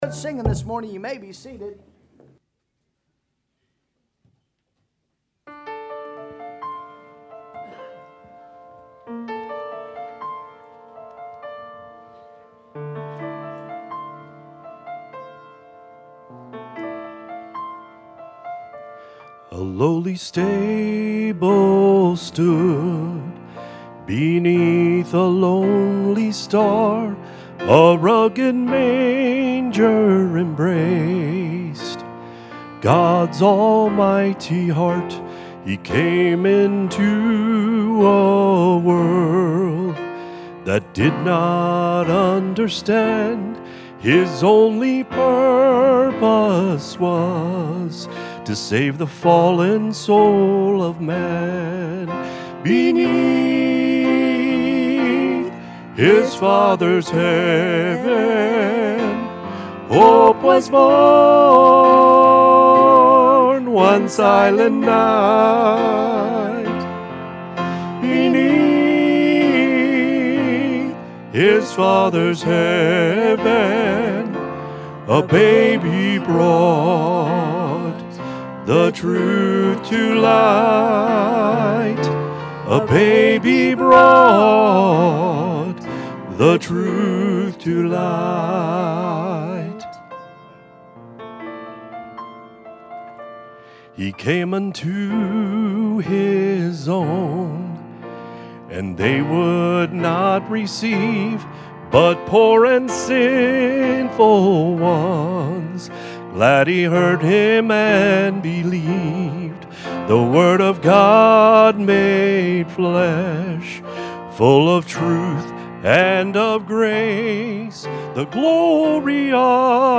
God’s Message to Mary | Sunday AM